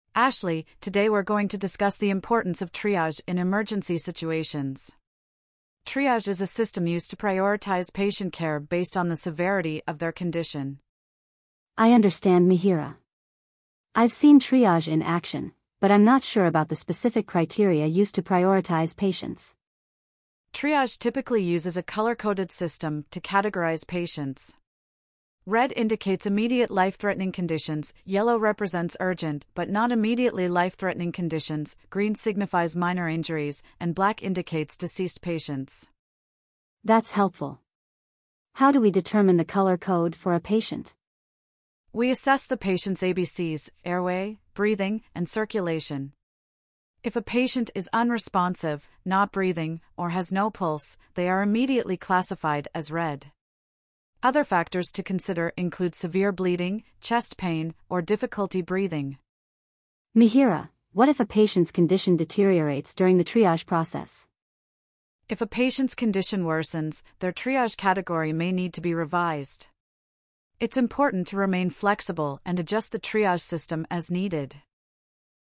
Rationale: The conversation highlights the primary purpose of triage, which is to prioritize patient care based on the severity of their condition.